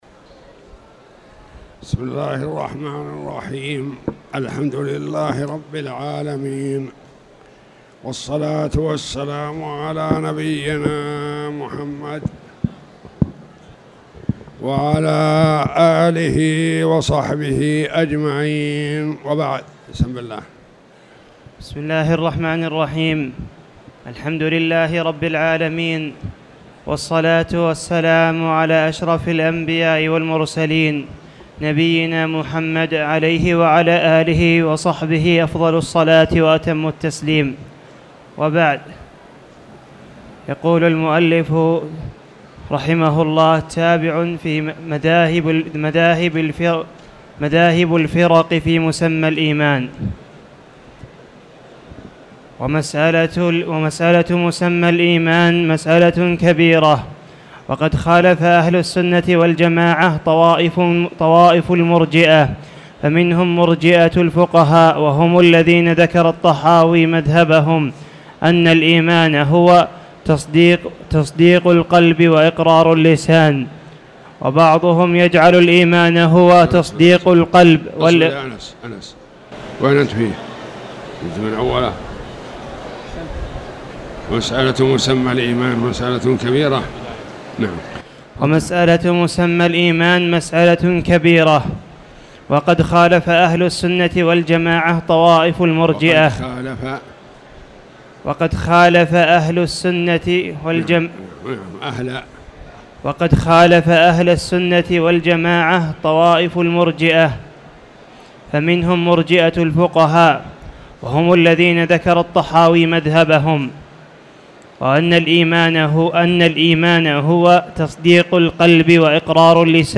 تاريخ النشر ٢٧ شوال ١٤٣٧ هـ المكان: المسجد الحرام الشيخ